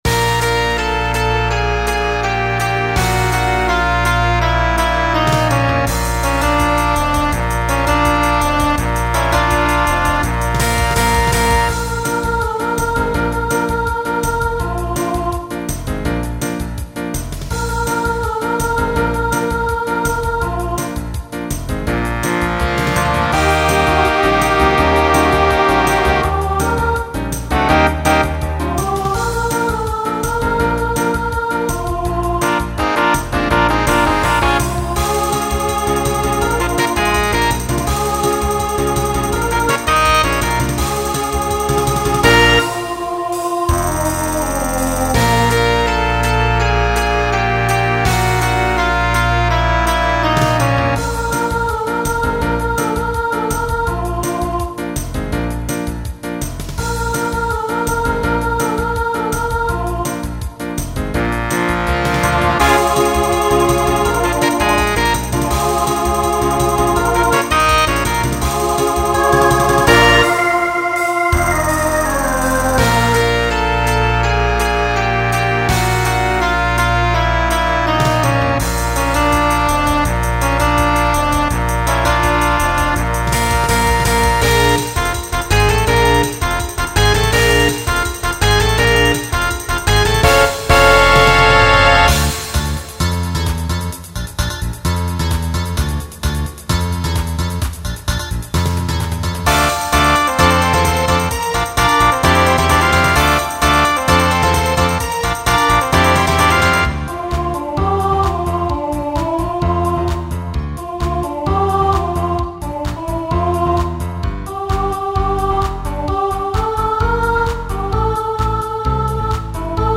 Genre Pop/Dance Instrumental combo
Transition Voicing SSA